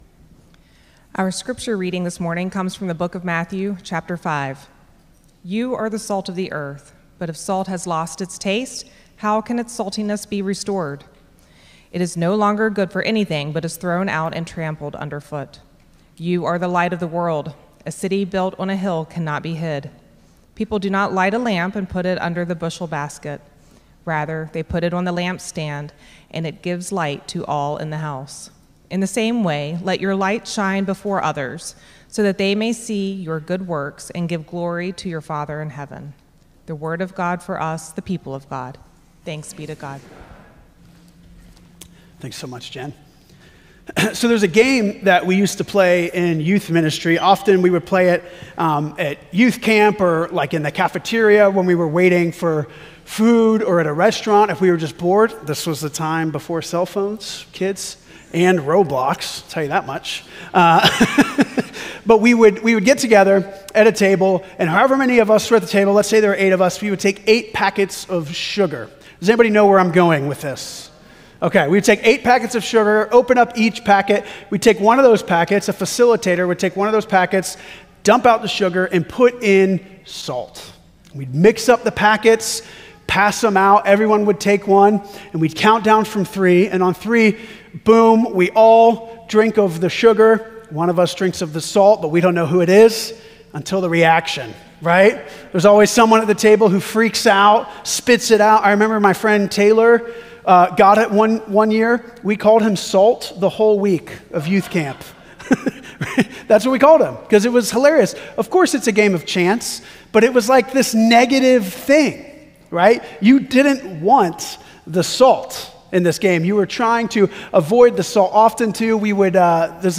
“Red Letter Life” Sermon Series, Week 1